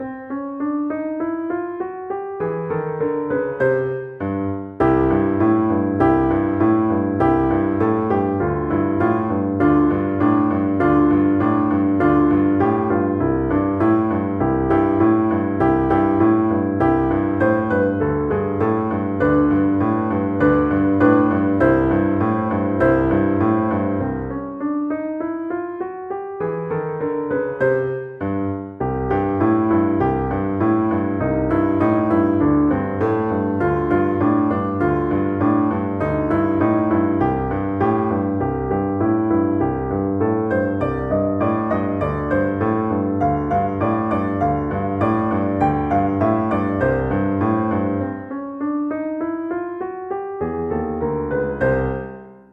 No parts available for this pieces as it is for solo piano.
2/4 (View more 2/4 Music)
Energico
Pop (View more Pop Piano Music)